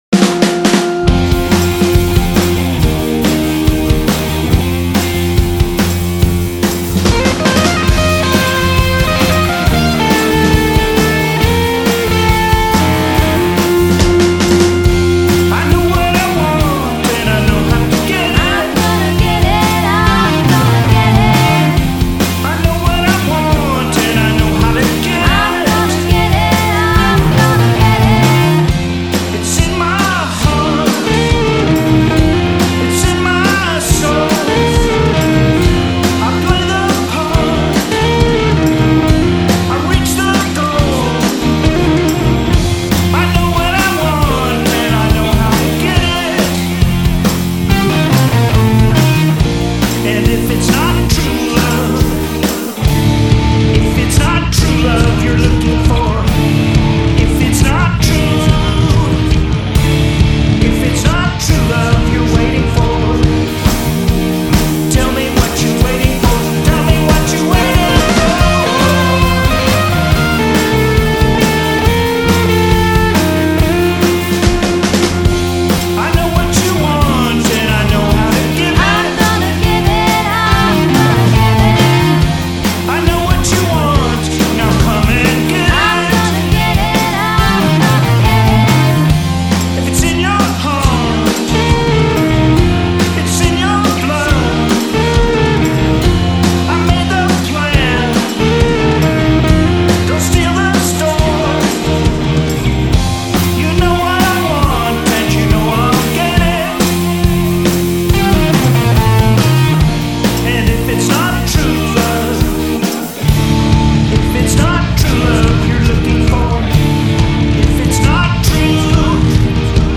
Vocals, Guitars, Bass, Drums, Organ, Percussion & Synthesizers.